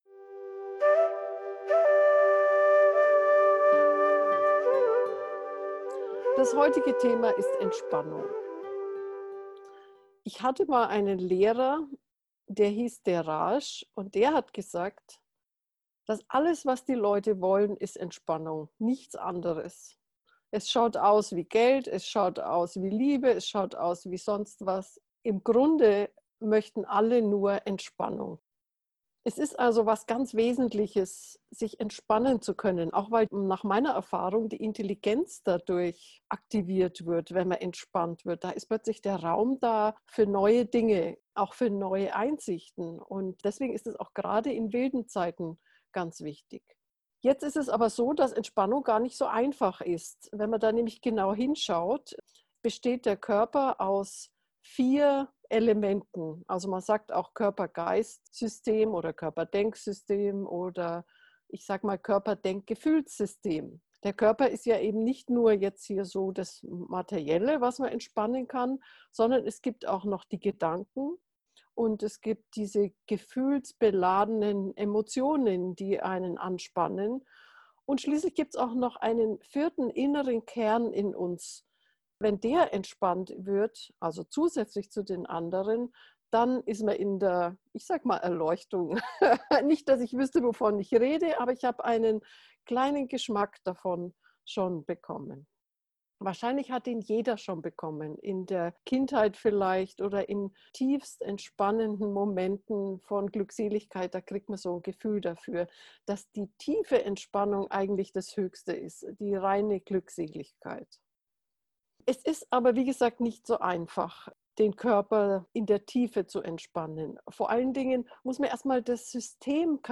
Kurze Anleitungen